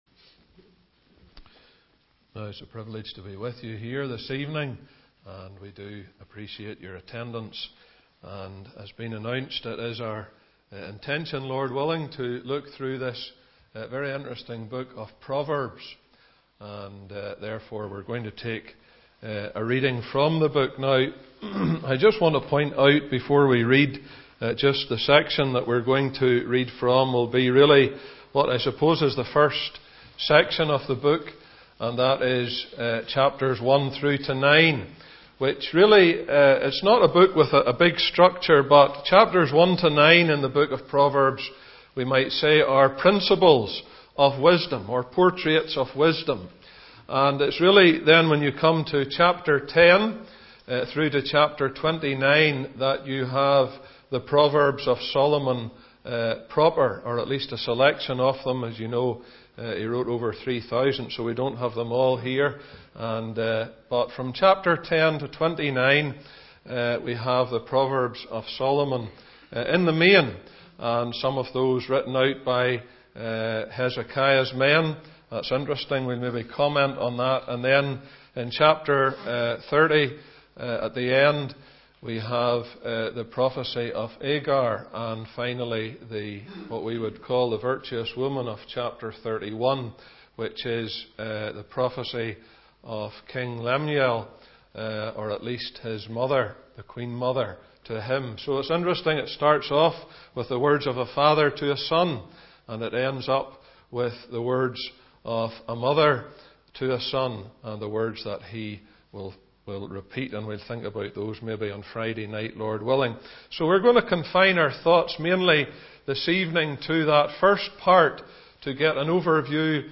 Service Type: Bible Teaching